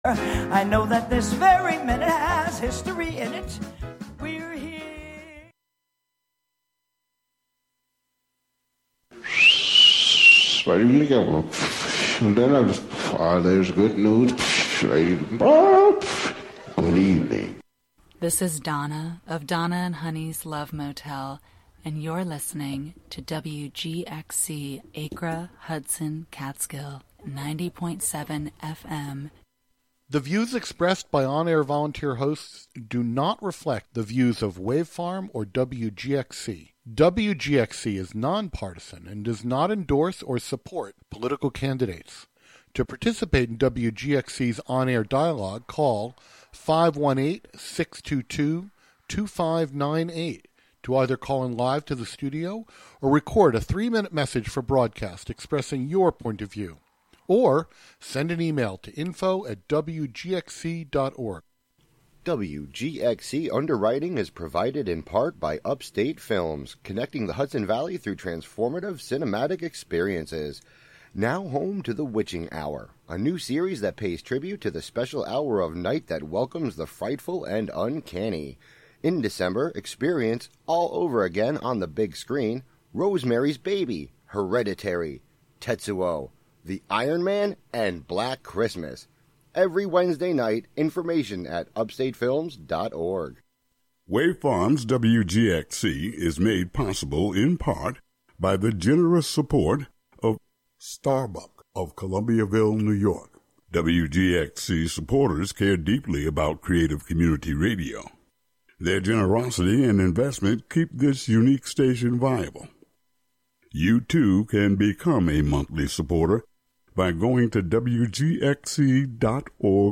" a sonic collage of this month's dream themes collected from listener submissions. The broadcast contains field recordings, music, interviews, archival poetry readings, etc.
"Auditions" features prospective WGXC volunteer programmers trying out their proposed radio programs on air for listener feedback.